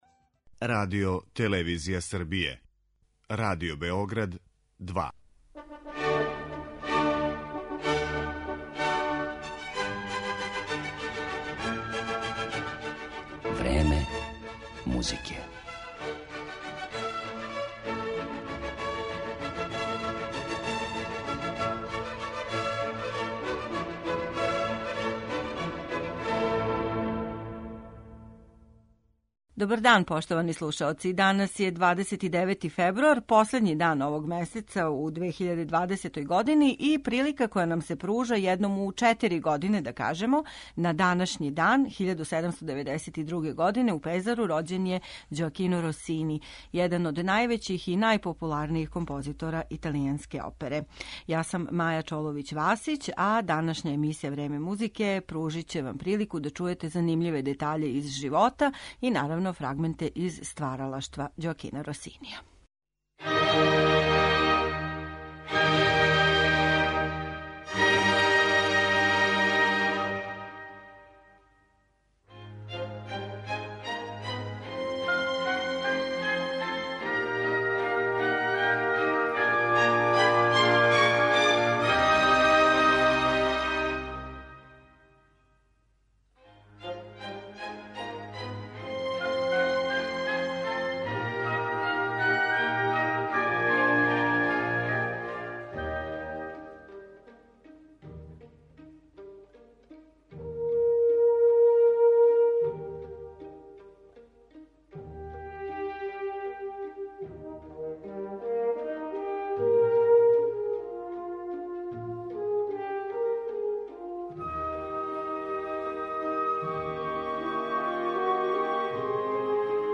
Неколико оперских фрагмената, као и делове његовог инструменталног и духовног опуса